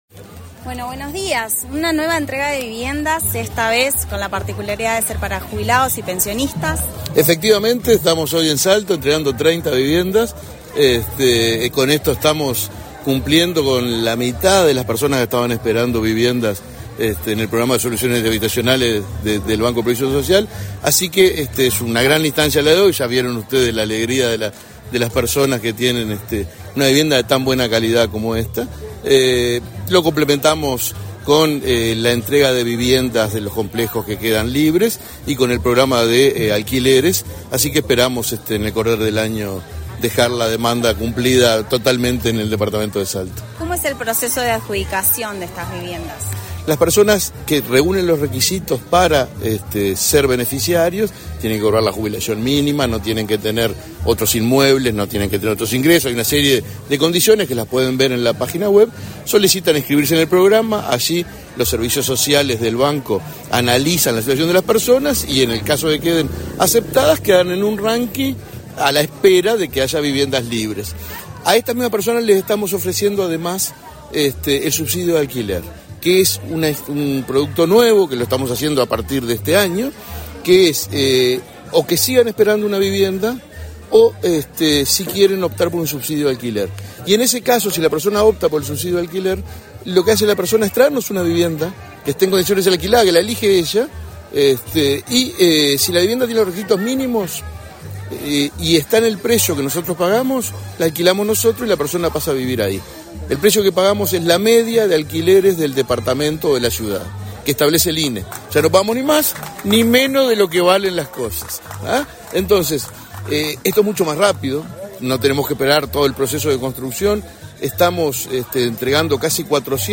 Entrevista al presidente de BPS, Alfredo Cabrera